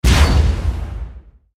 archer_skill_siegestance_03_charge.ogg